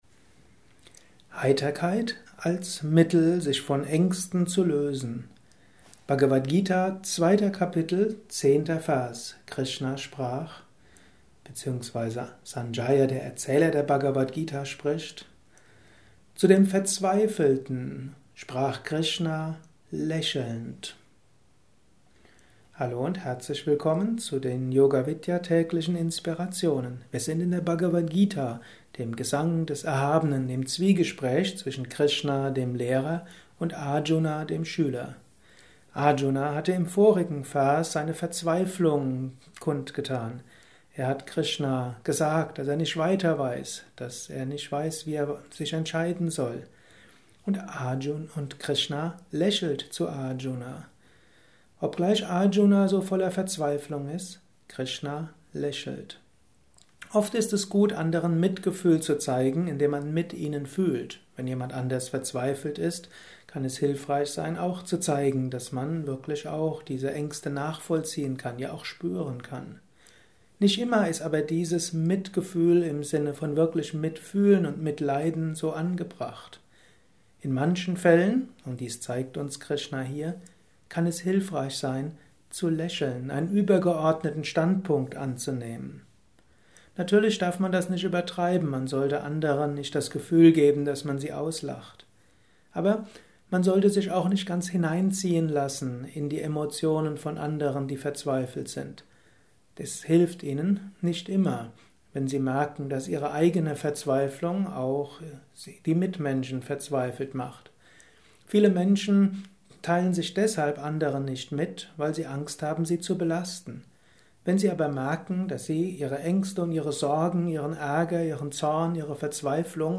Dies ist ein kurzer Vortrag als Inspiration für den heutigen Tag